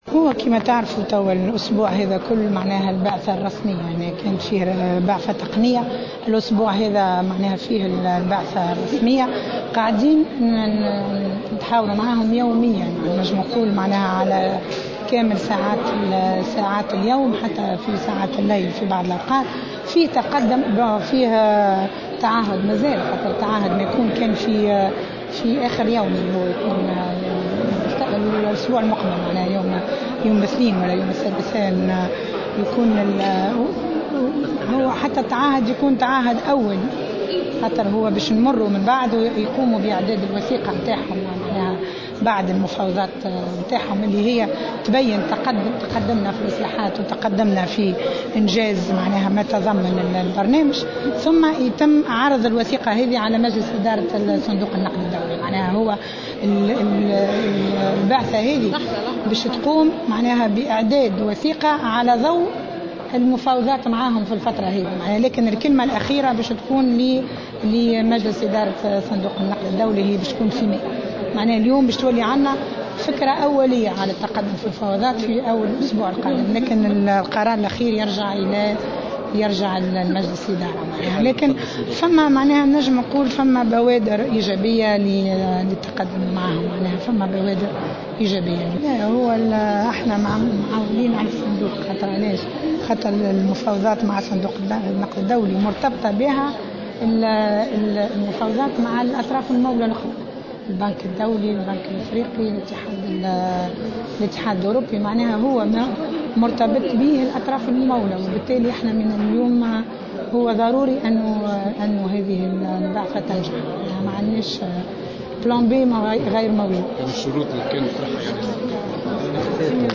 وتحدثت الوزيرة، في تصريح لمراسل الجوهرة أف أم، على هامش انعقاد جلسة عامة المصادقة على المخطط التنموي الخماسي 2016- 2020 ، اليوم الأربعاء، عن "بوادر إيجابية" في المحادثات بين الطرفين، مشيرة إلى أنه سيتم أخذ فكرة أولية حول قرار مجلس إدارة صندوق النقد الدولي مطلع الأسبوع القادم.